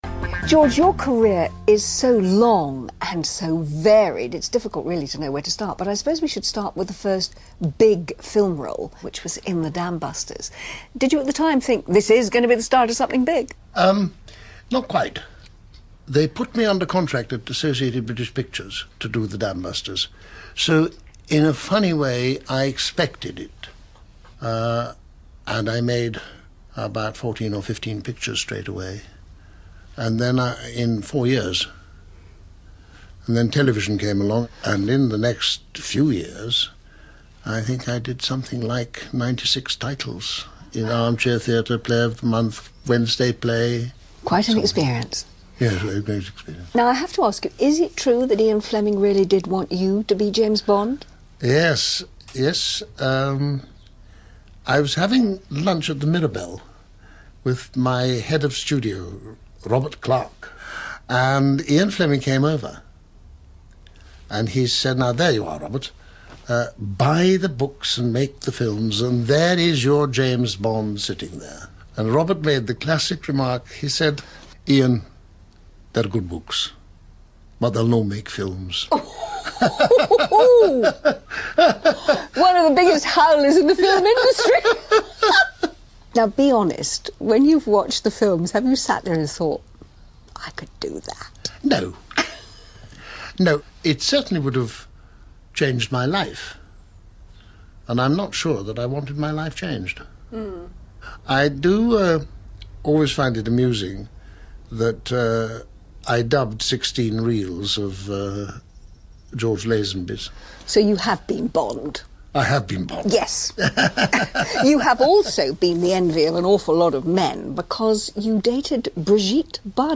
Angela Rippon and the Cash in the Celebrity Attic team visit much-loved Inspector Wexford star George Baker in his beautiful country cottage. George hopes to raise £700 for his local youth club.